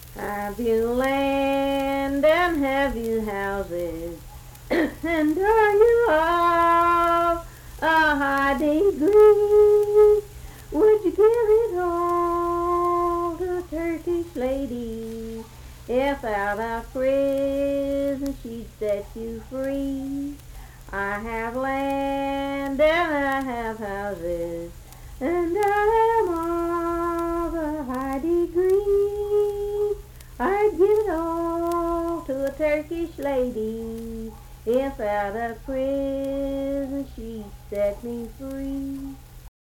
Unaccompanied vocal music
Verse-refrain 2(4).
Voice (sung)
Nicholas County (W. Va.), Richwood (W. Va.)